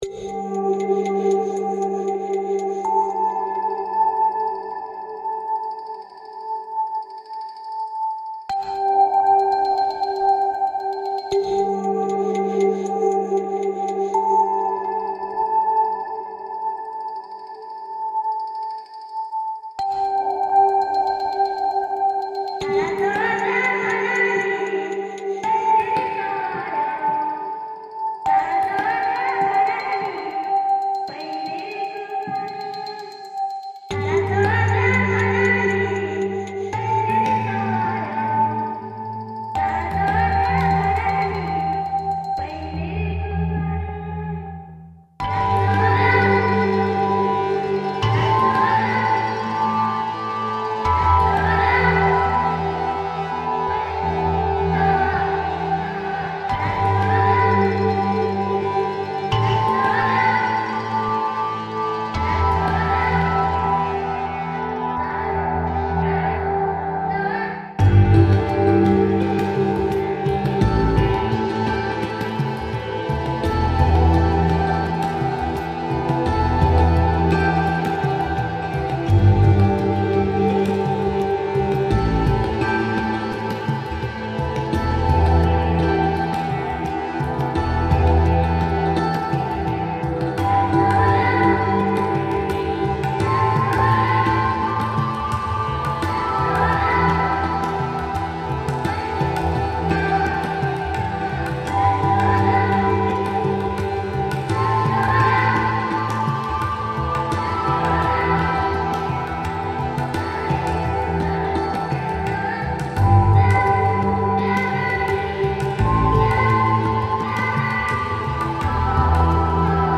The women sang the same melodic phrase over and over with slight changes.
The original melody was in quite a complex time signature so I used short samples of the voices and played them in common time. I recreated the original melody and variations of it in different instruments which would be common to the area. I wanted the voices to sound as though they were calling from within the trees and echoing through the valley to tempt you to follow.
Women singing with flute